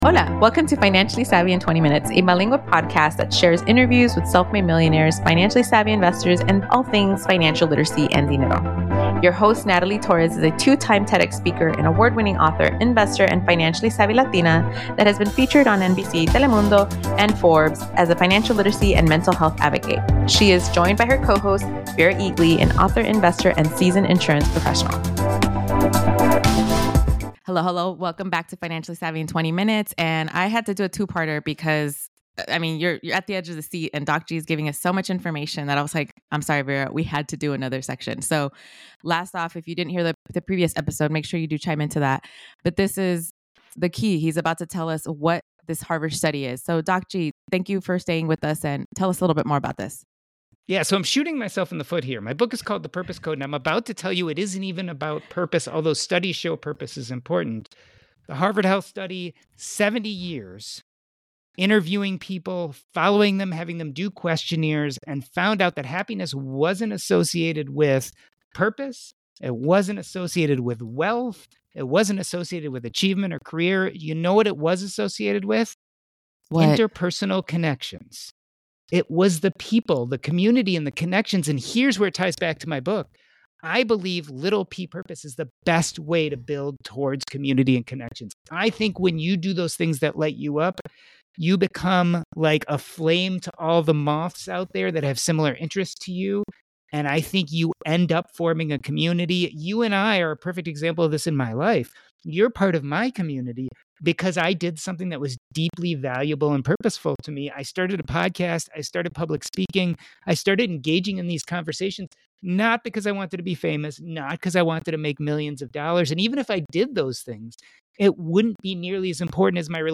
Welcome back to Part 2 of our interview